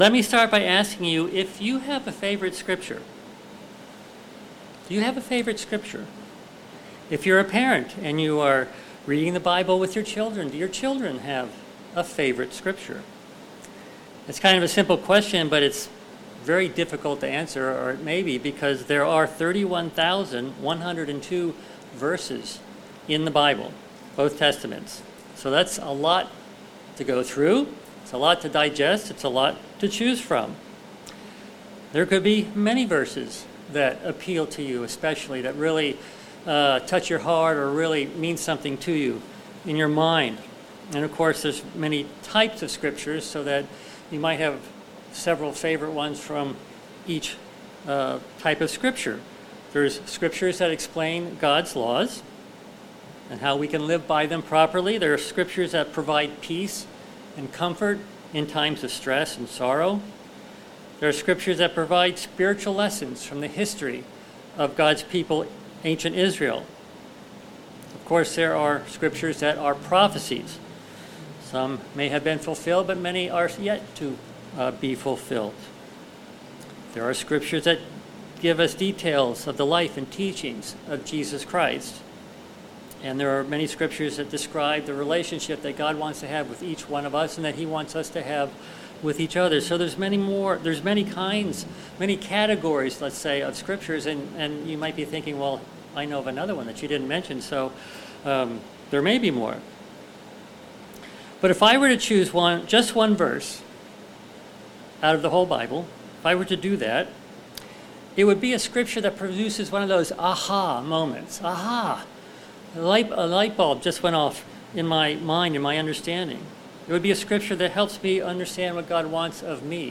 Sermons
Given in Delmarva, DE